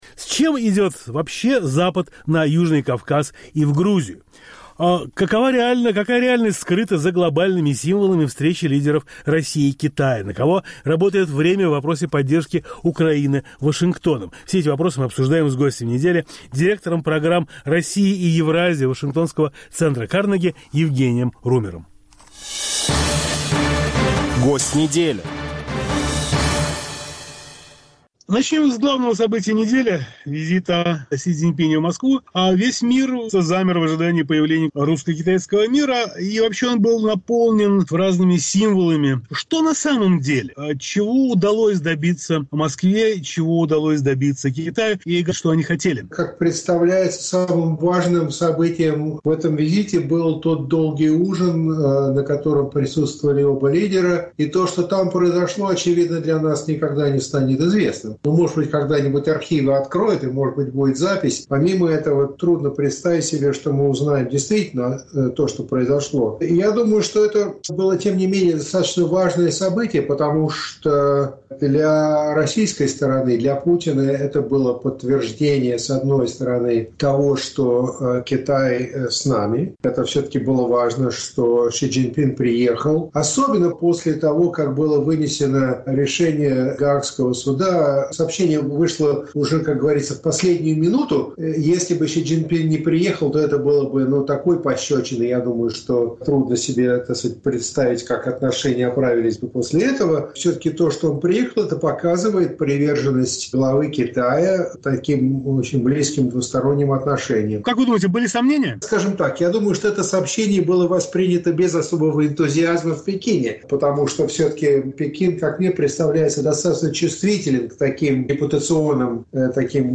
С Гостем недели обсуждаем главные мировые события, включая Южный Кавказ